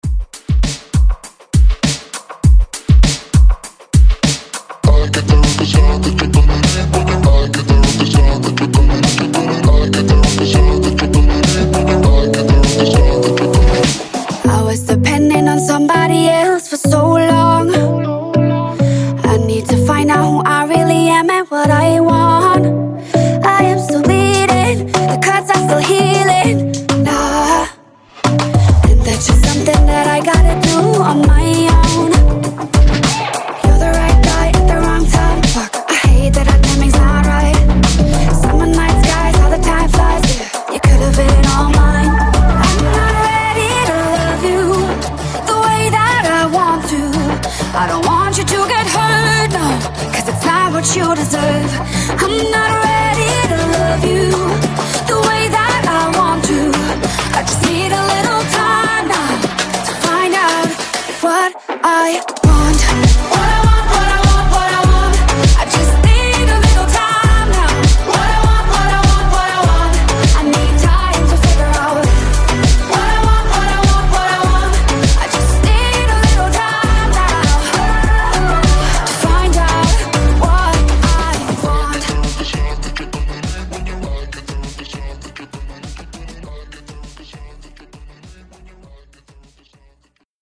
Genre: GERMAN MUSIC Version: Clean BPM: 100 Time